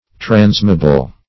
Meaning of transmeable. transmeable synonyms, pronunciation, spelling and more from Free Dictionary.
Search Result for " transmeable" : The Collaborative International Dictionary of English v.0.48: Transmeable \Trans"me*a*ble\, Transmeatable \Trans`me*at"a*ble\, a. [L. transmeabilis.] Capable of being passed over or traversed; passable.